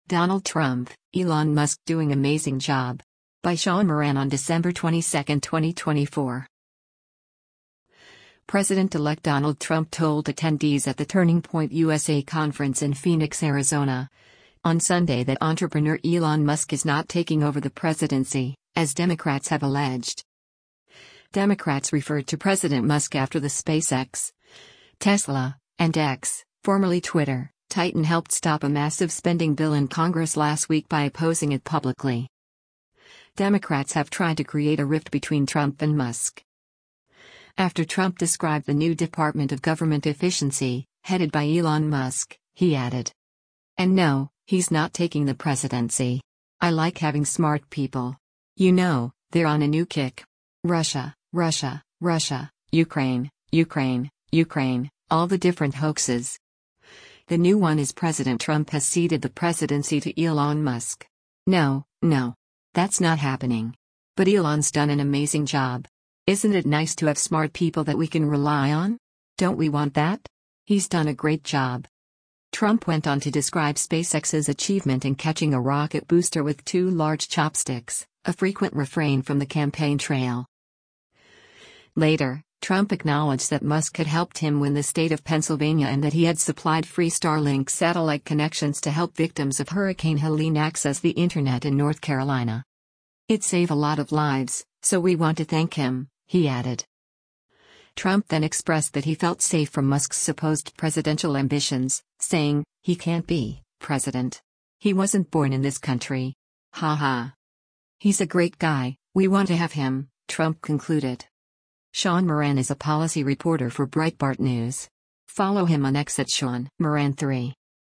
President-elect Donald Trump told attendees at the Turning Point USA conference in Phoenix, Arizona, on Sunday that entrepreneur Elon Musk is not taking over the presidency, as Democrats have alleged.